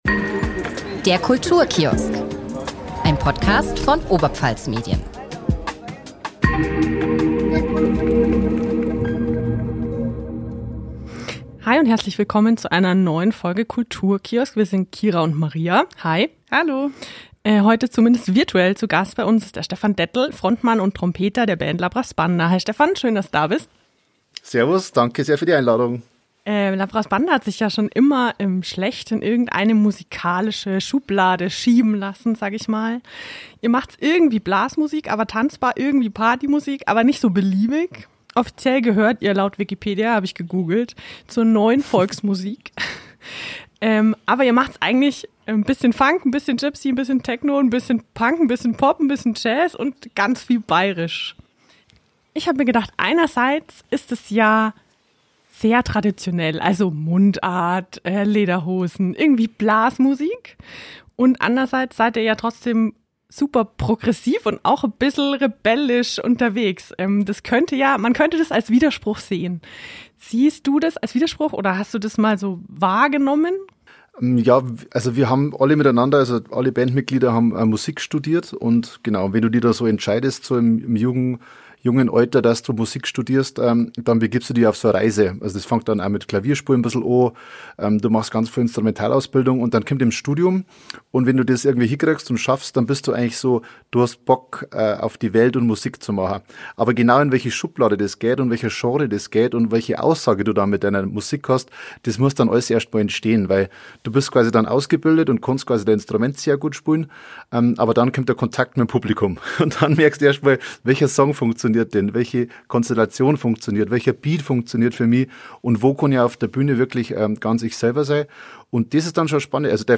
Die Letzte war Redebeitrag von Michael Kellner (Parlamentarischer Staatssekretär für Wirtschaft und Klimaschutz/B90/Grüne) am 12.04.2024 um 16:39 Uhr (164. Sitzung, TOP ZP 19).